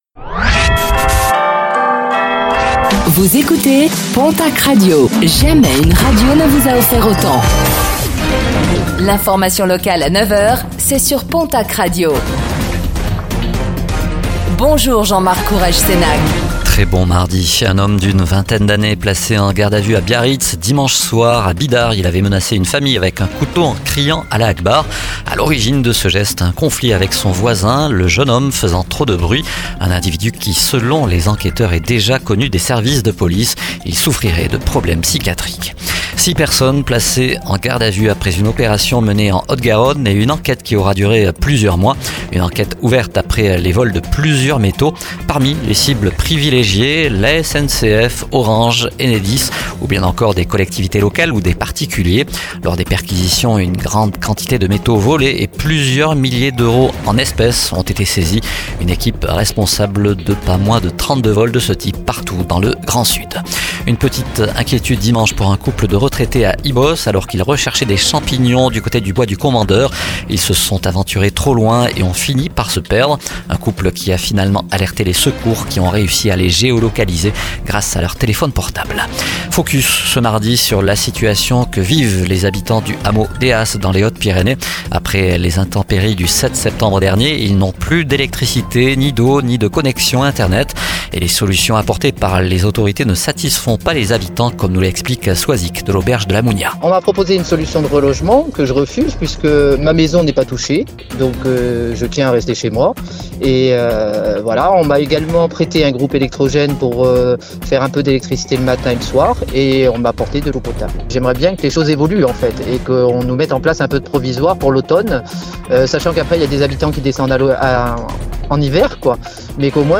09:05 Écouter le podcast Télécharger le podcast Réécoutez le flash d'information locale de ce mardi 24 septembre 2024